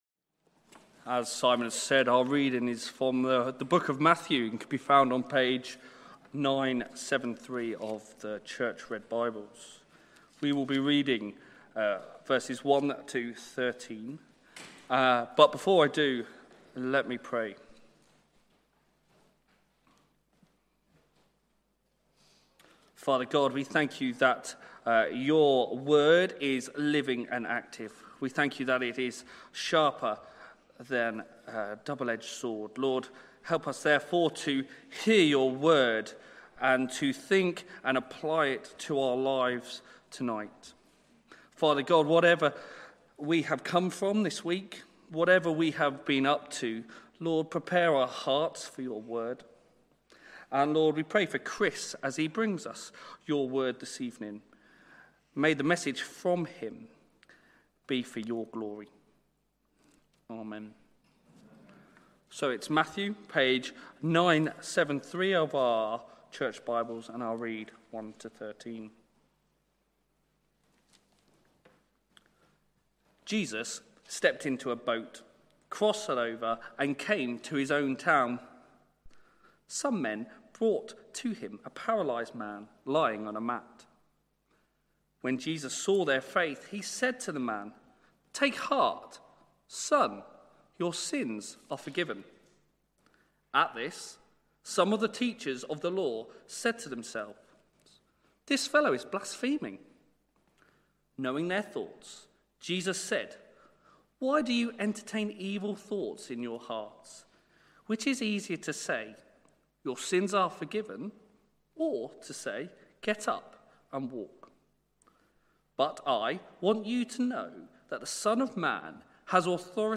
Sermons Archive - Page 44 of 188 - All Saints Preston